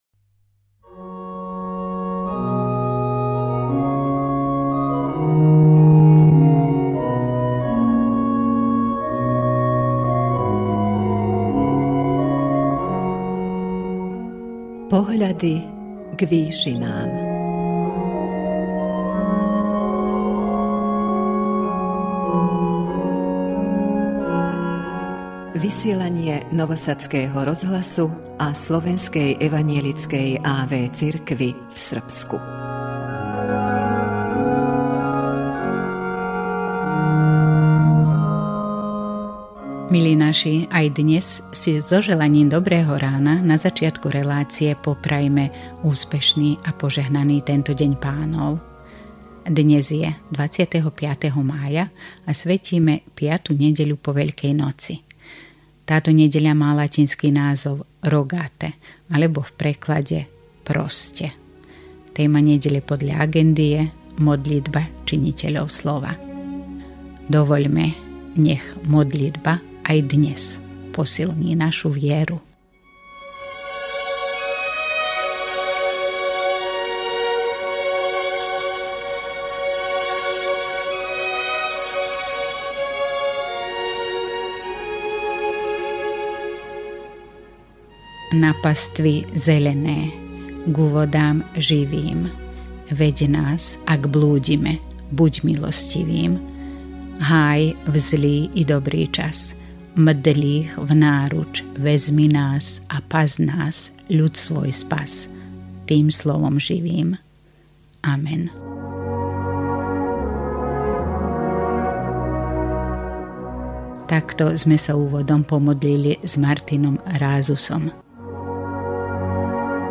duchovnou úvahou